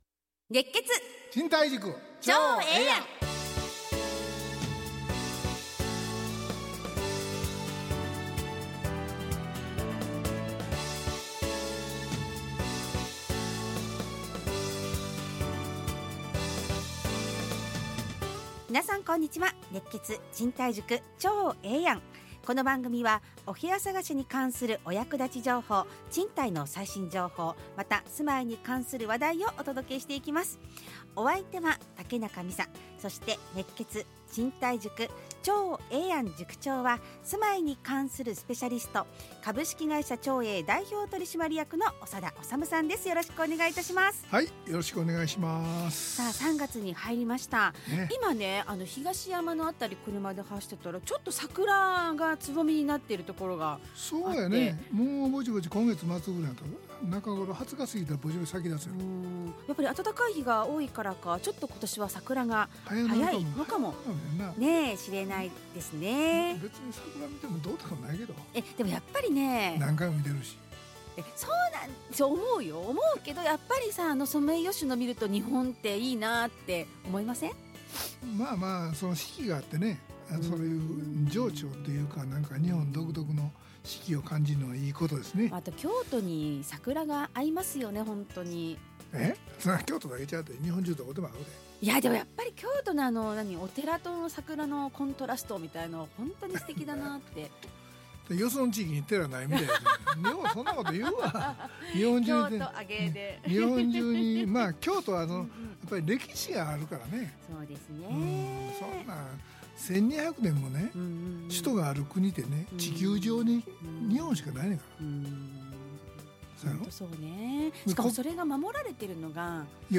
ラジオ放送 2026-03-06 熱血！賃貸塾ちょうええやん【2026.3.6放送】 オープニング： 桜の蕾が・・・、中東情勢 ちょうえぇ通信：長栄の各部署をご紹介 長栄マンスリーマンション京都 賃貸のツボ：契約更新したばかりなのに、マンションのオーナーから半年後に退居してほしいと言われました。